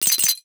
NOTIFICATION_Glass_13_mono.wav